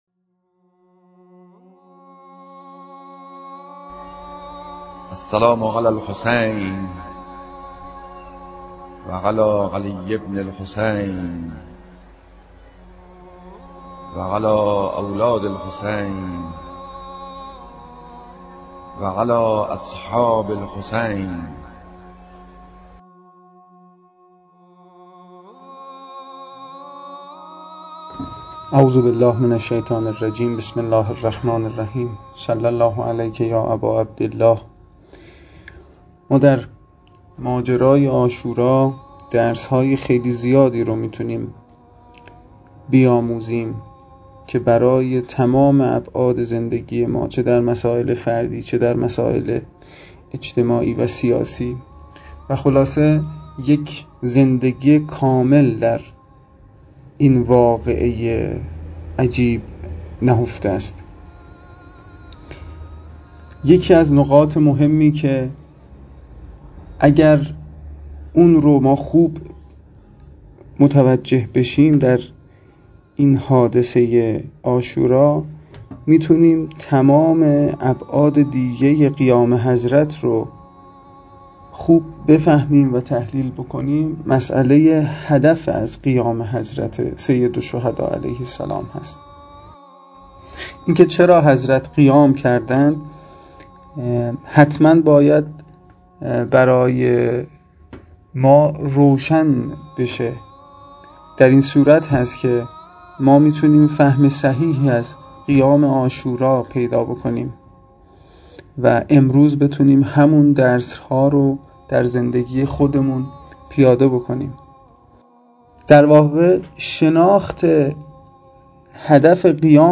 پادپخش بیانات